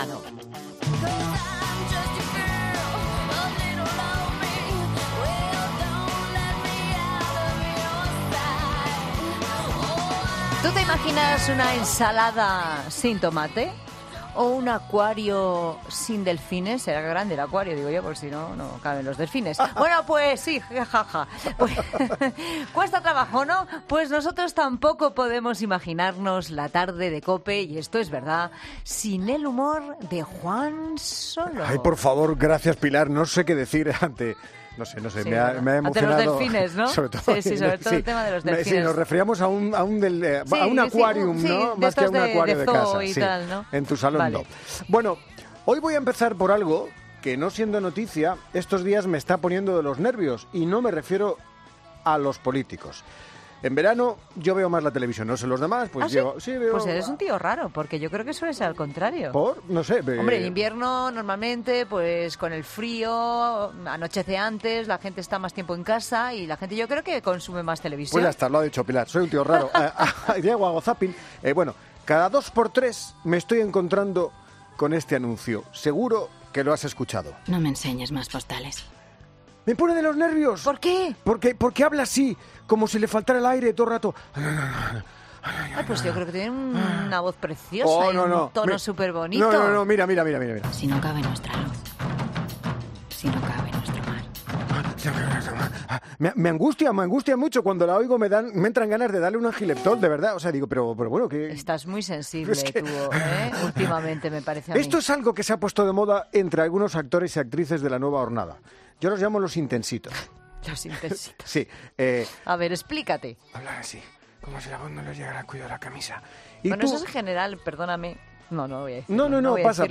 Humor con Juan Solo. ¿Quieres saber como los niños hacen millonarios a sus padres jugando con marcianitos?